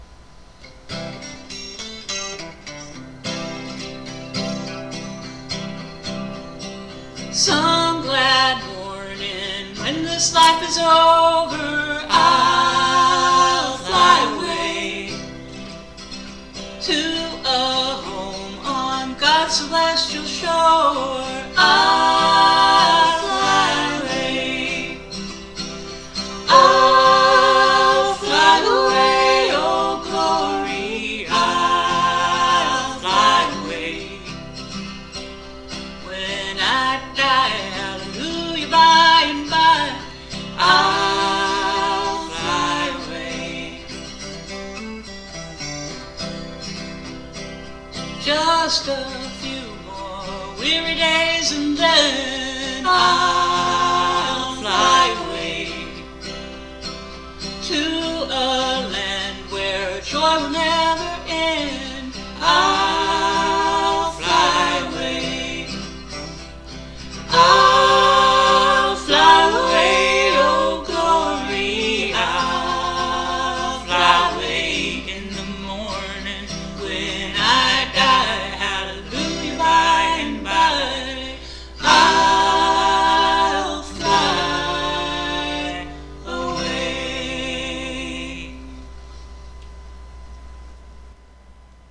12 String Guitar, All Vocals